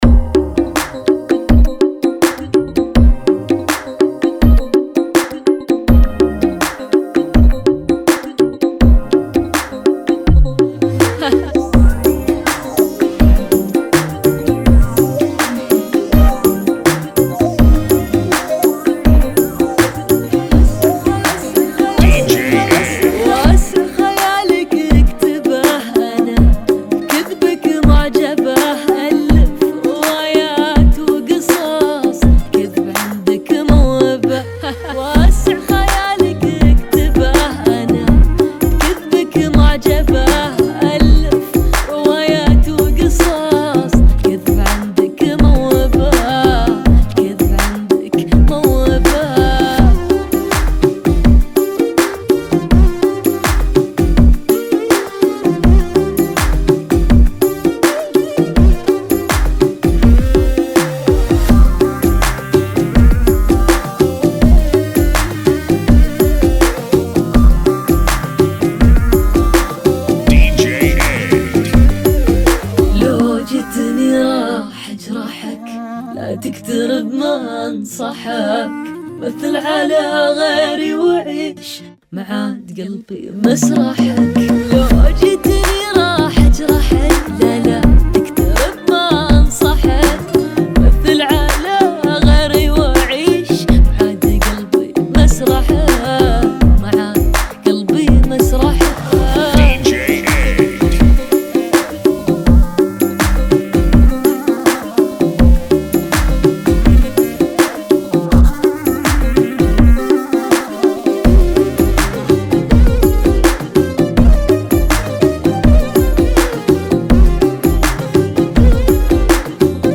Funky [ 82 Bpm